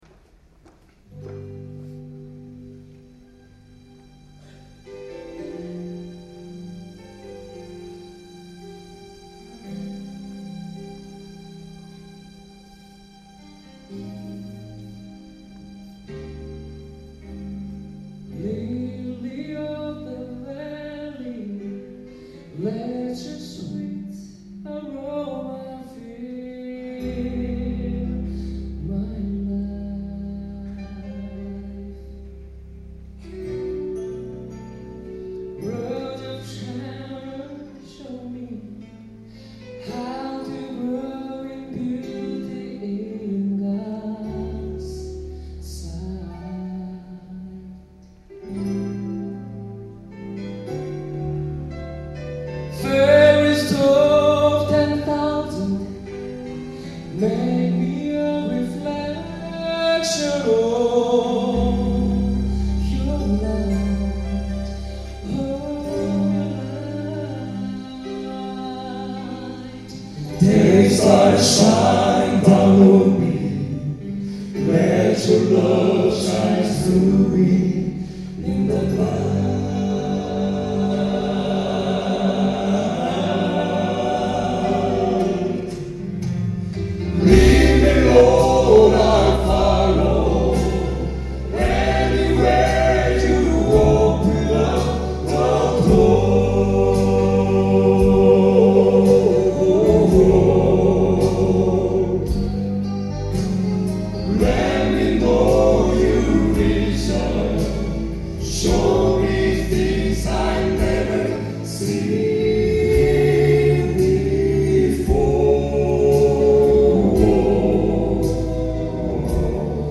I took the opportunity to test out the in-ear binaurals I got from The Sound Professionals and borrowed an old MiniDisc player.
These songs are best heard on headphones to hear the direction of chatter, laughter and so on.
Levels a little too low here. The Golden Angels – Daystar: